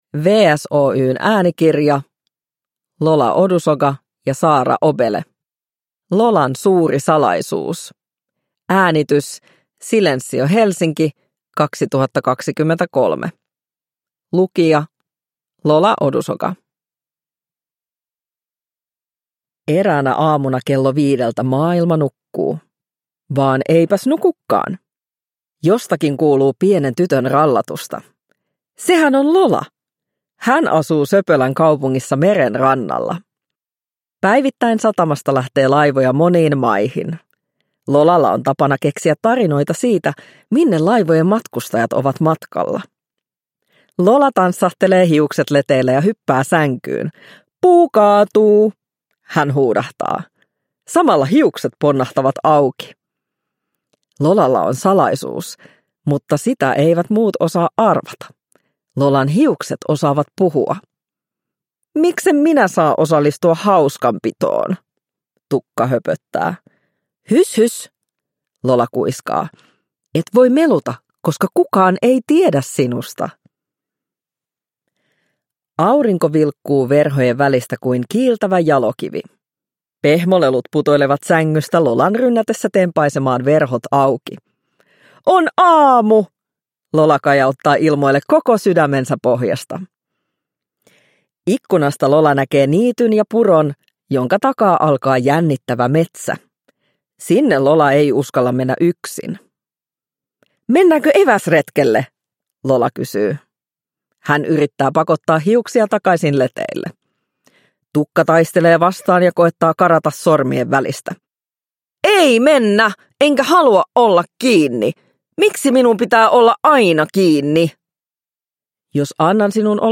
Uppläsare: Lola Odusoga